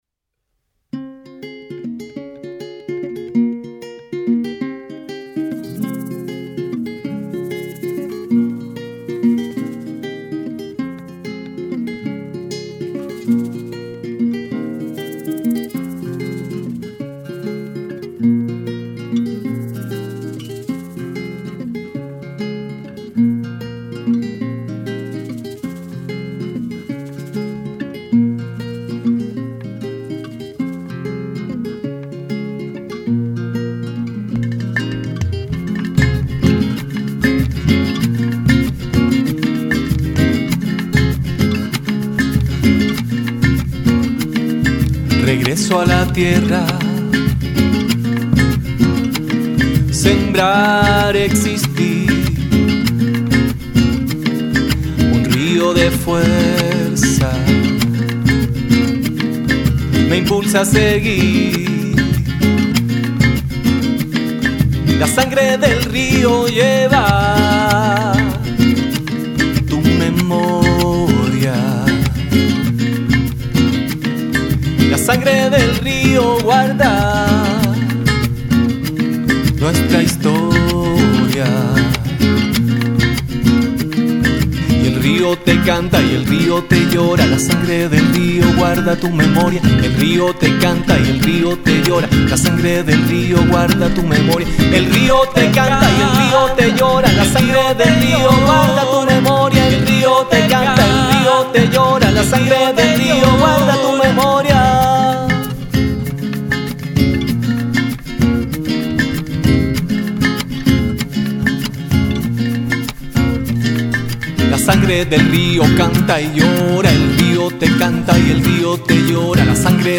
Canción
Guitarra.
Odu, shaker, agua y semillas.